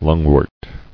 [lung·wort]